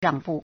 让步 (讓步) ràngbù